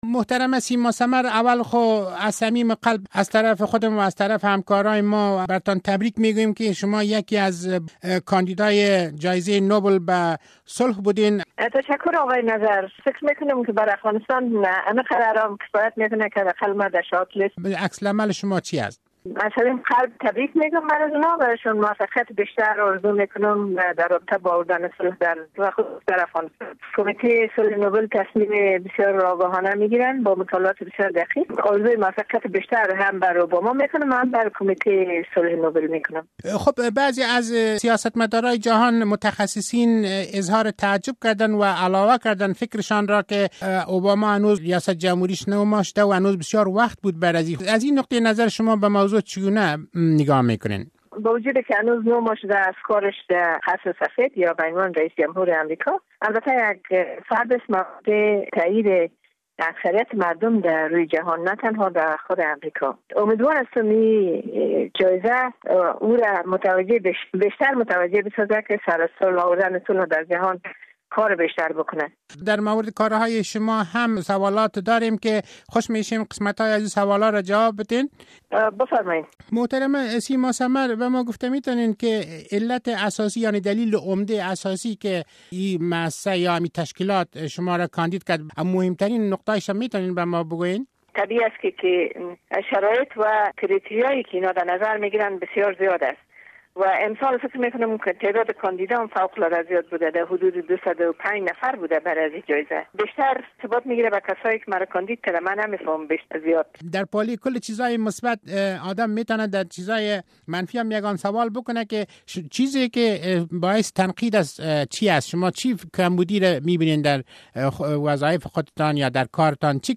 مصاحبهء رادیو آزادی با سیما سمر